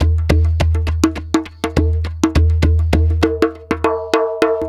TR PERCS 2.wav